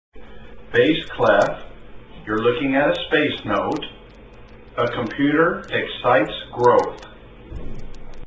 there is a piano lesson audio instruction file to be played